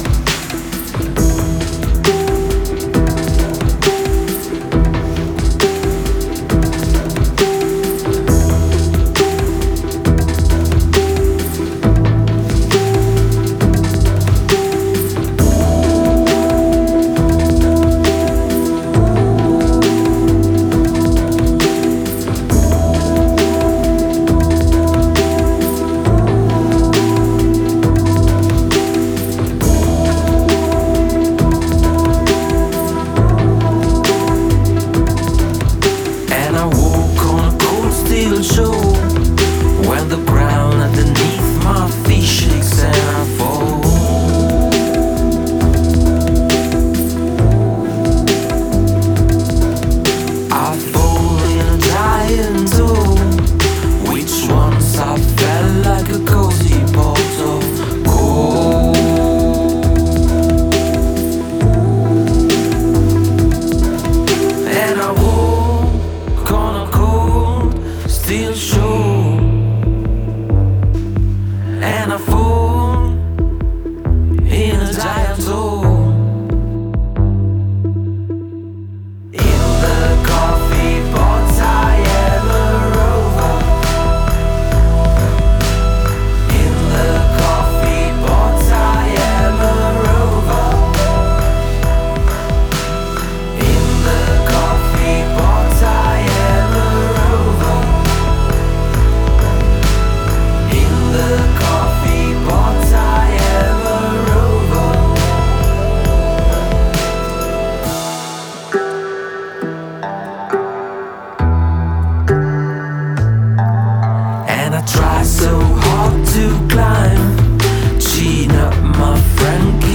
Intervista Typo Clan 9-3-2018 | Radio Città Aperta